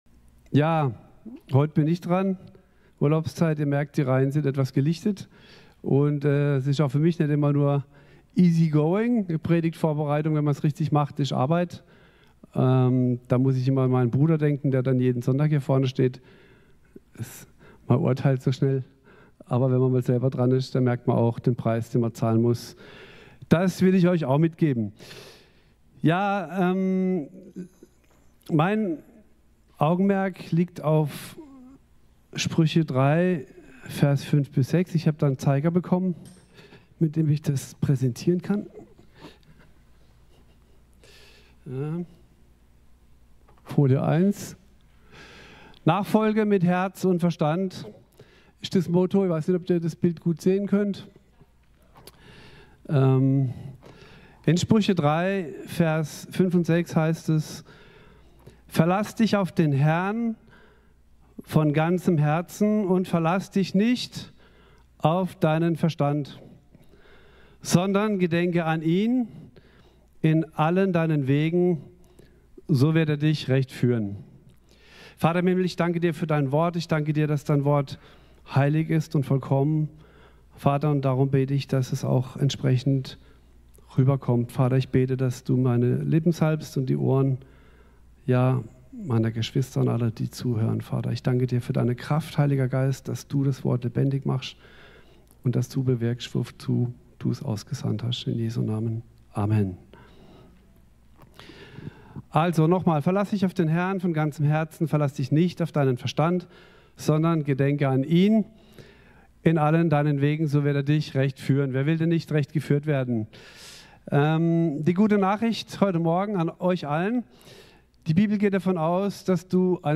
10.08.2025 Ort: Gospelhouse Kehl